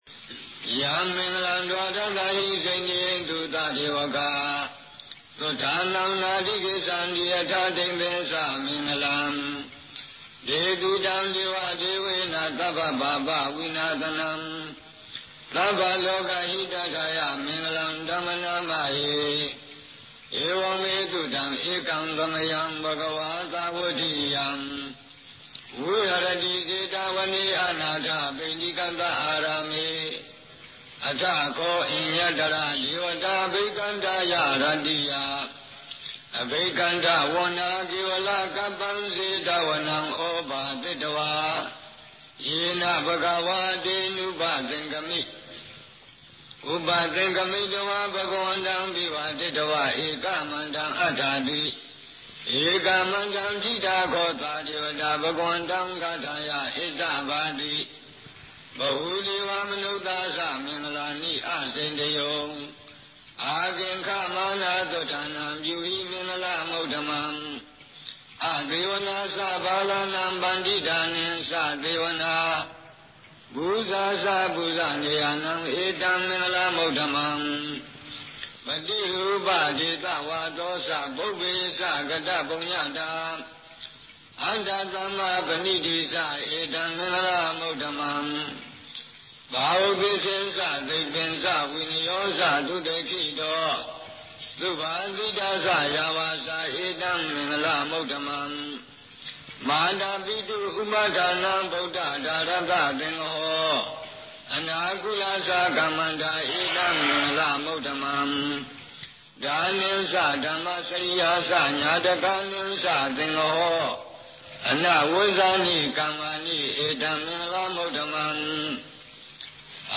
To learn the correct pronunciation, you can listen to the chanting of Mingun Sayādaw U Vicittasāra of Burma.